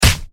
punch7.ogg